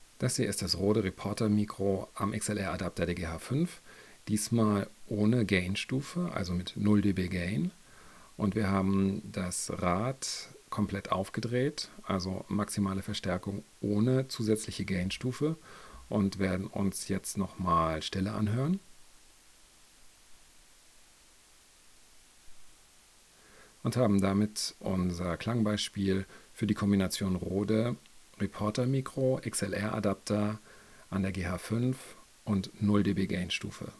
Als nächstes folgt der Härtetest mit dynamischem Mikrofon.
Rode Reporter dynamisches Mikro bei 0 dB Boost am XLR-Adapter
Beim Test mit dem dynamischen Mikrofon gerät der XLR-Adapter - wie erwartet - an seine Grenzen.
RodeReportDyna_XLR_GH5_0DBBoost_norm.wav